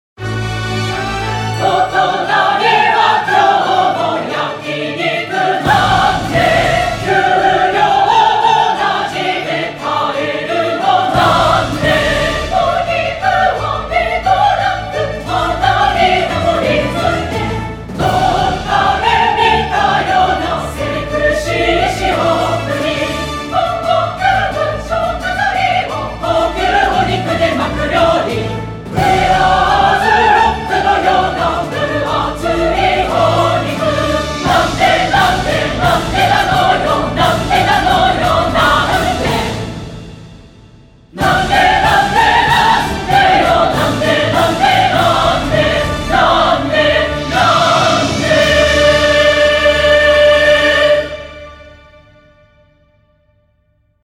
ポイントで差がつき、暮らしにも差が出てしまう悲哀を、合唱団の本気の歌声で表現したコミカルな楽曲になりました。
ACOUSTIC / CLASSIC